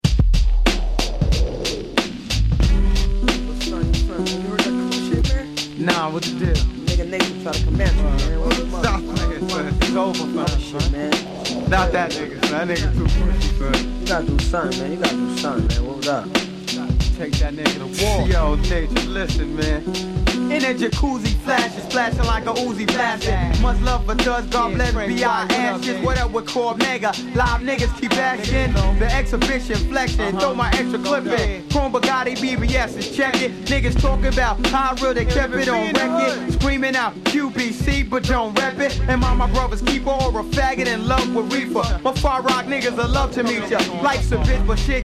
98' Nice Street Hip Hop !!